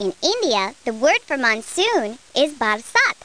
00576_Sound_monsoon.India.mp3